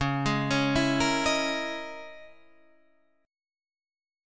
C# Minor 6th Add 9th